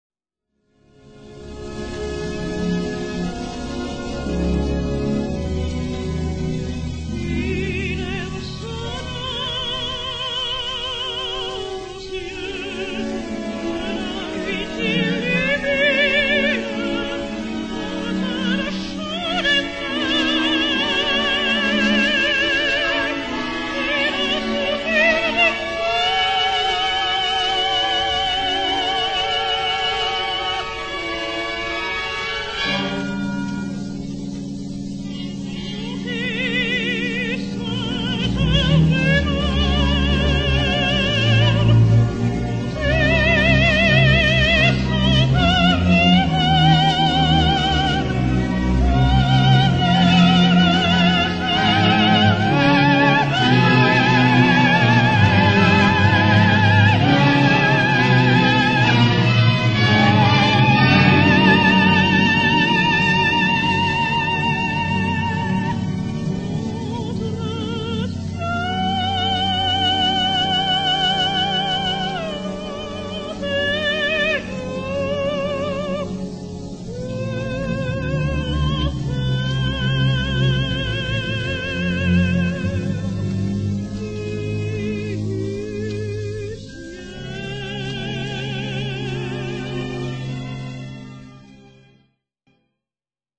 soprano
disque 78 tours